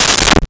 Umbrella Open 03
Umbrella Open 03.wav